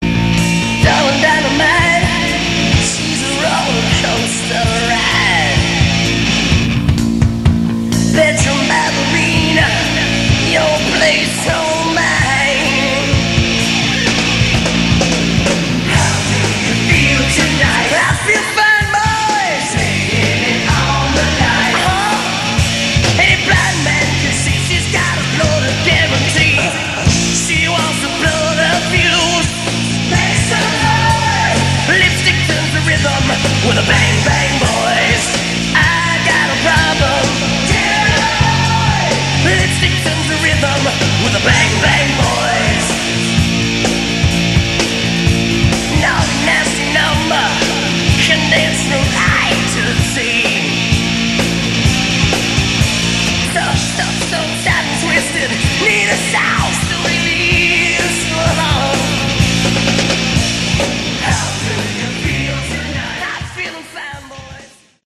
Category: Hard Rock
lead vocals